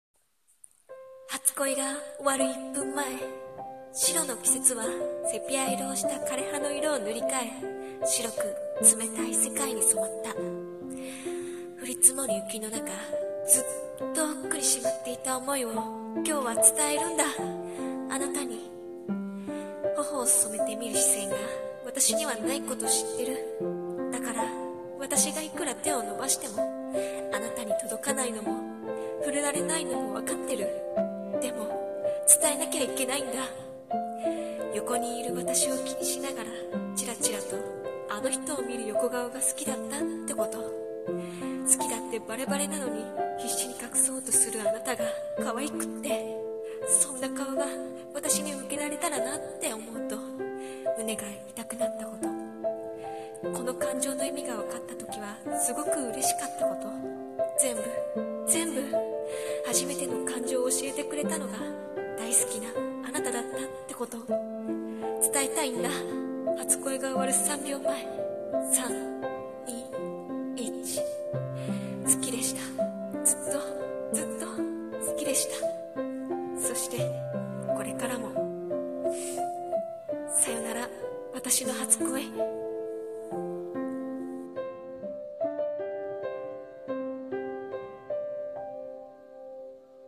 【１人声劇】